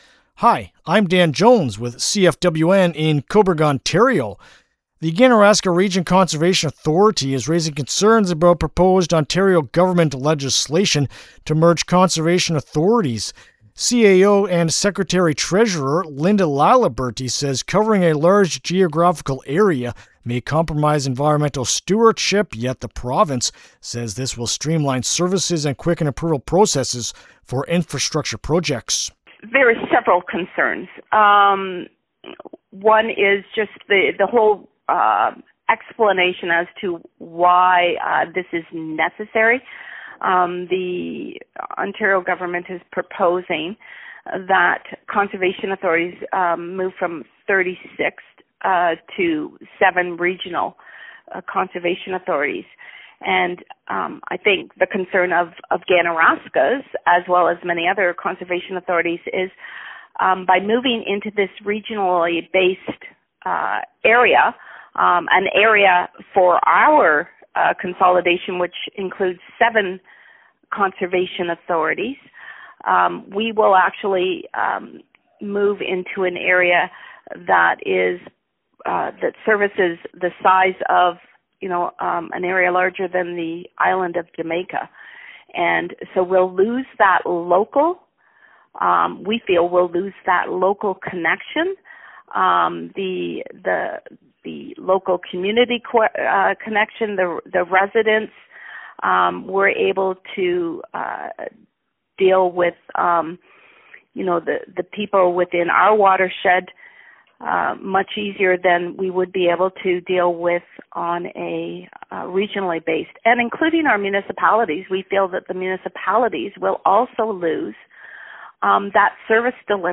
Ganaraska-Conserv.-Interview-LJI.mp3